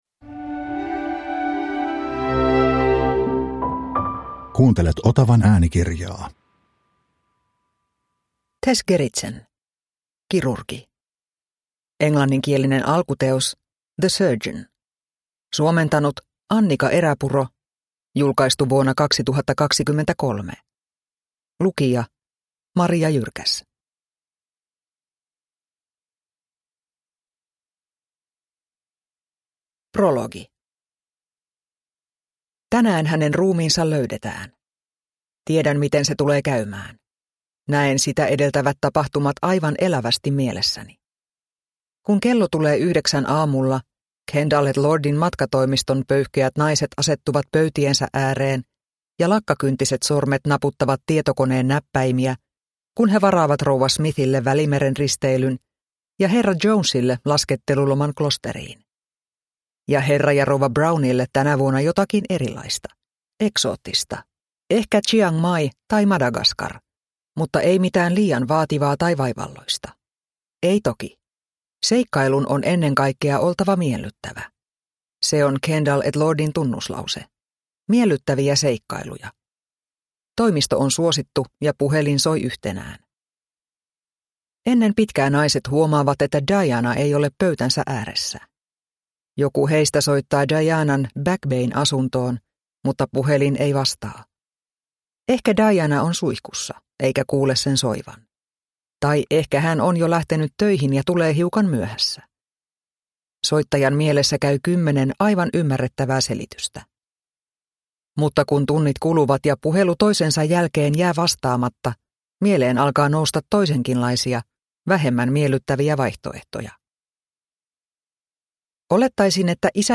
Kirurgi – Ljudbok – Laddas ner